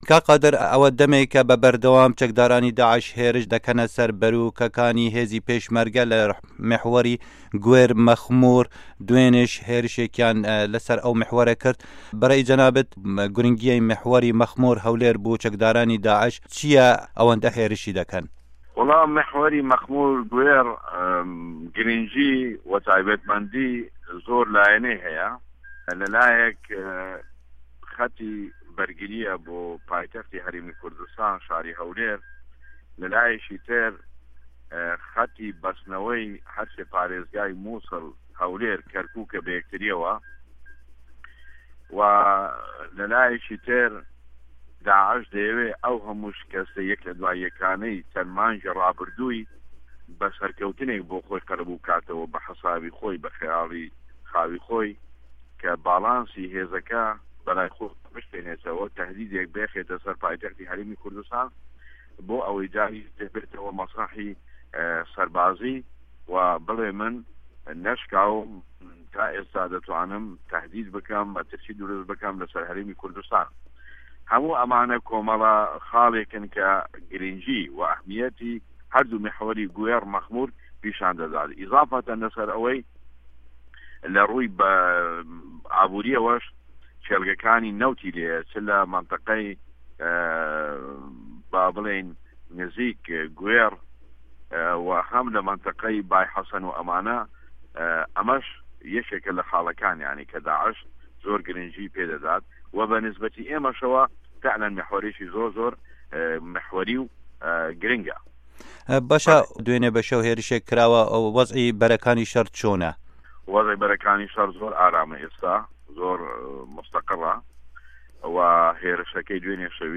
Hevpeyvin digel Qadîr Hesen